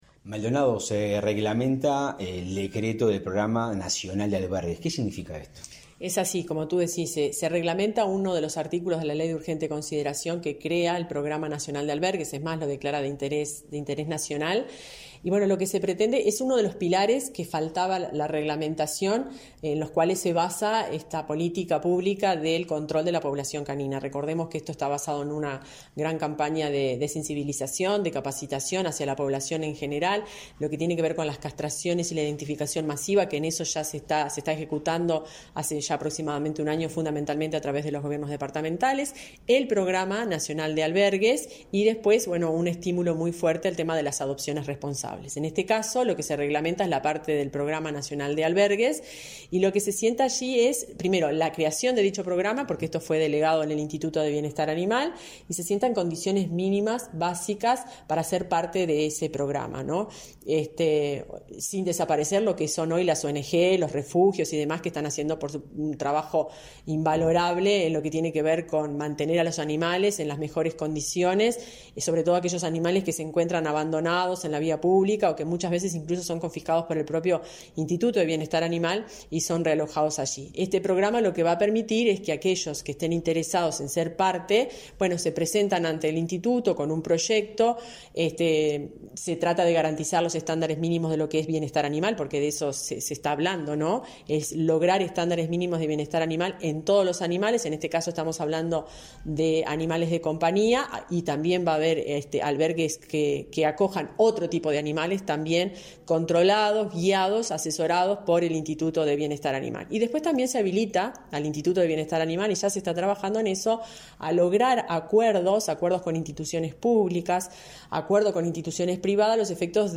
Entrevista a la directora general del MGAP, Fernanda Maldonado